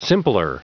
Prononciation du mot simpler en anglais (fichier audio)
Prononciation du mot : simpler